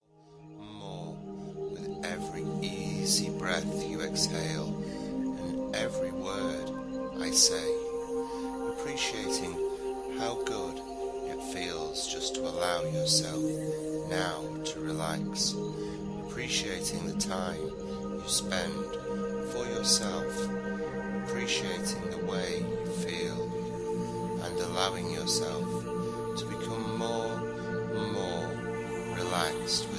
01 - Hypnosis - Excercise Motivation